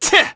Neraxis-Vox_Attack3_kr.wav